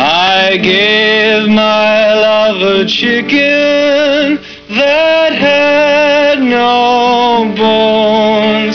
chicken2.wav